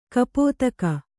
♪ kapōtaka